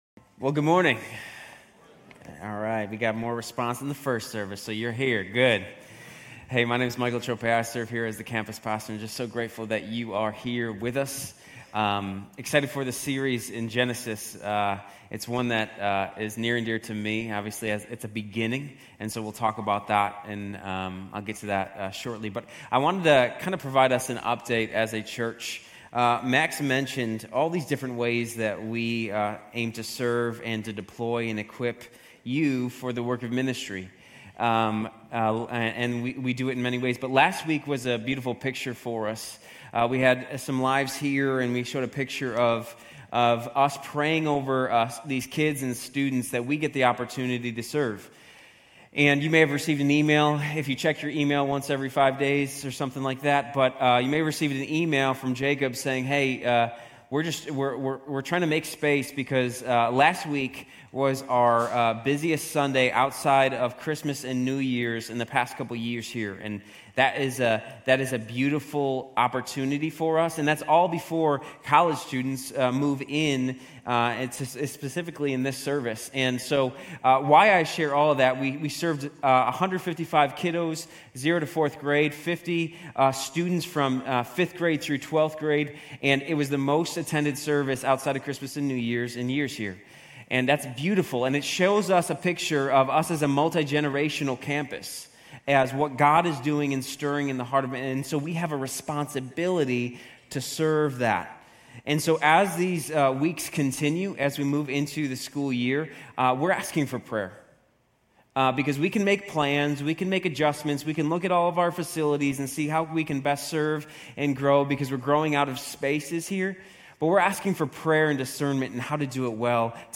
Grace Community Church University Blvd Campus Sermons Genesis - Creator Aug 18 2024 | 00:36:09 1x 00:00 / 00:36:09 Subscribe Share RSS Feed Share Link Embed